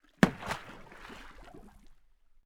Water_24.wav